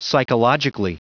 Prononciation du mot psychologically en anglais (fichier audio)
Prononciation du mot : psychologically